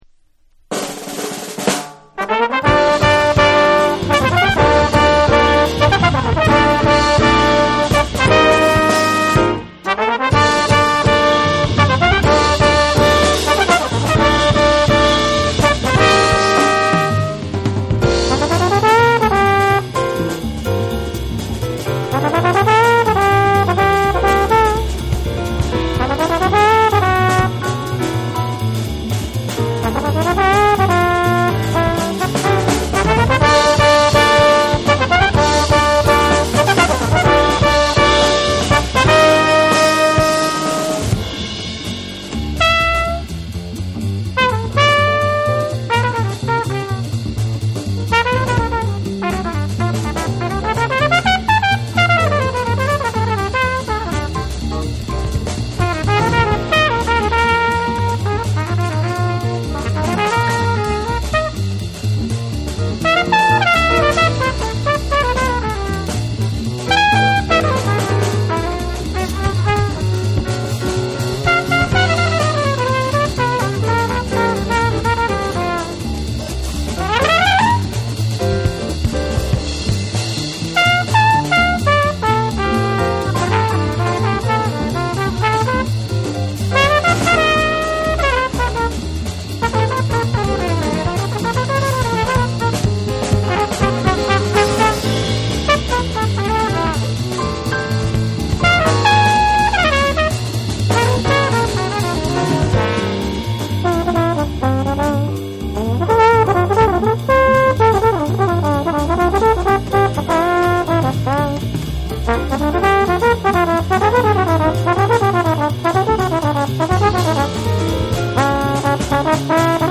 モダン・ジャスとクラブ・ジャズを繋ぐ、いつの時代にも通用するクオリティーの高いジャズ・ナンバーを3曲収録。
JAPANESE / SOUL & FUNK & JAZZ & etc